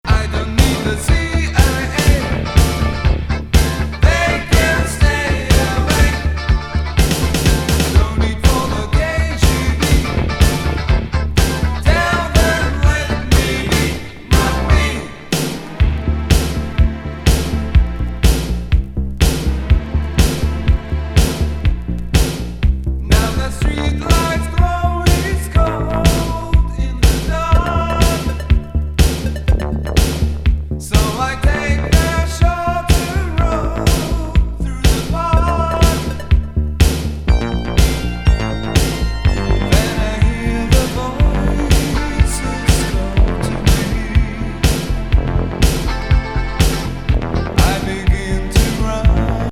シンセ80’S ROCK DISCO B面”CLUB MIX”が白眉!